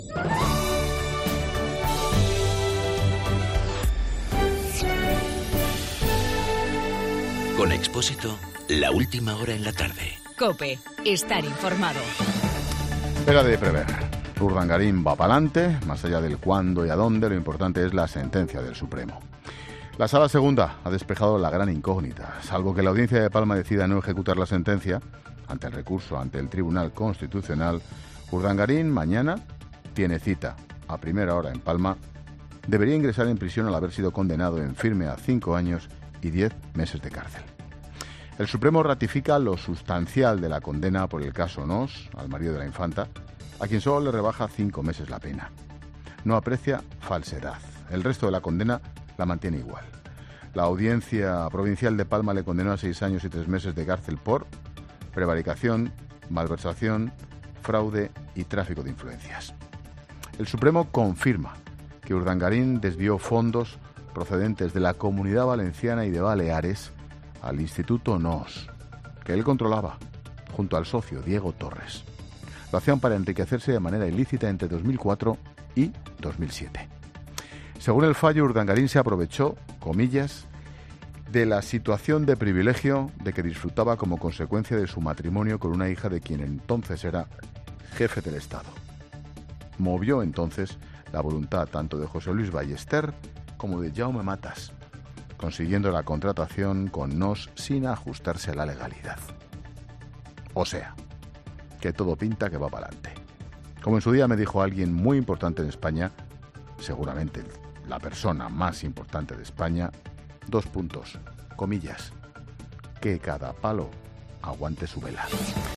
Monólogo de Expósito
El comentario de Ángel Expósito sobre la condena a Iñaki Urdangarín por el Tribunal Supremo.